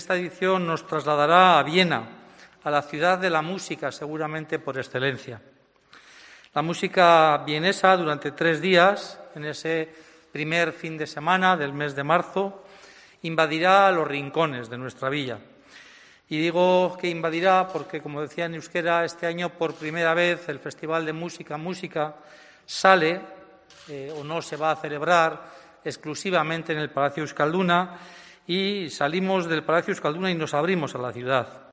Juan Mari Aburto, alcalde de Bilbao